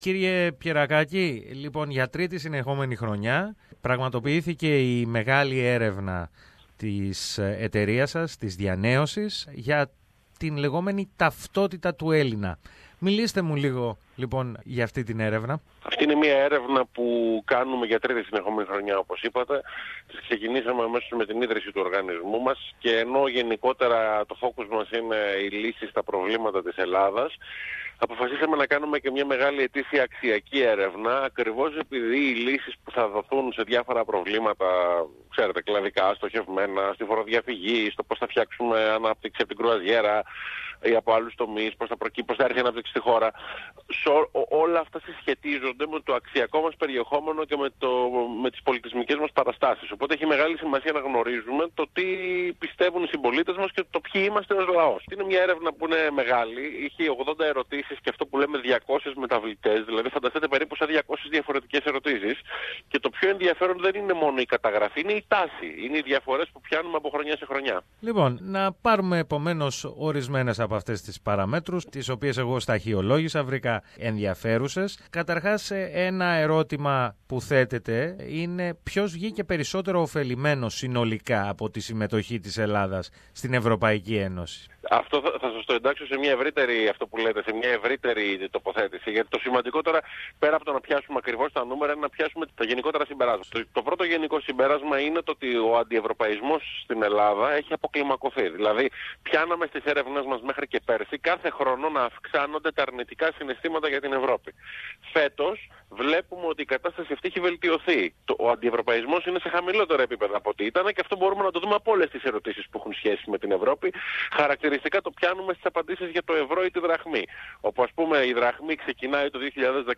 Όπως εξήγησε στο Ελληνικό πρόγραμμα, της ραδιοφωνίας SBS, ο Διευθυντής Ερευνών της ΔιαΝΕΟσις, Κυριάκος Πιερρακάκης, η φετινή έρευνα, καταδεικνύει αύξηση της συντηρητικότητας στην ελληνική κοινωνία, σε σχέση με πέρυσι, την ίδια στιγμή που παρατηρείται μια κάμψη του αντιευρωπαϊσμού.